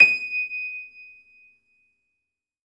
53d-pno23-D5.wav